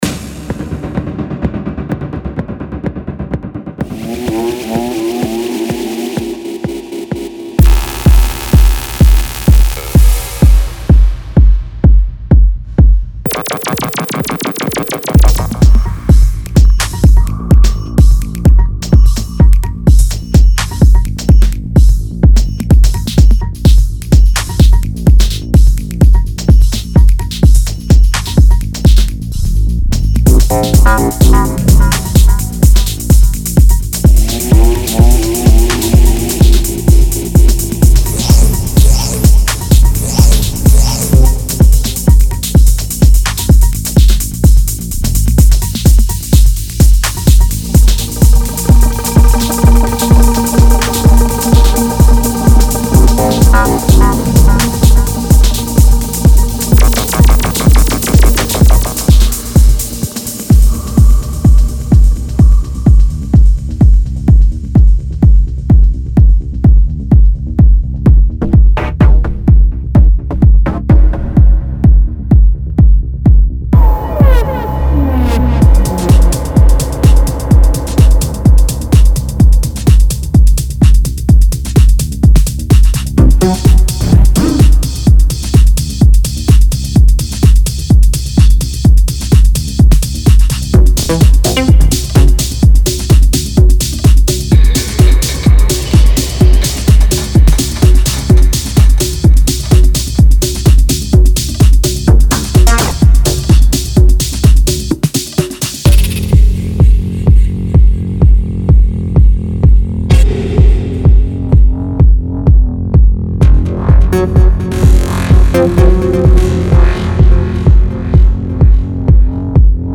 Genre:Techno
デモサウンドはコチラ↓
100 Lead Synth Loops (Dry & Wet)
100 Bass Loops (Incl. Sidechain)
24 Ambient Synth Loops